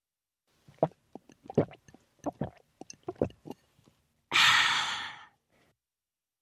Звук ребенка пьющего сок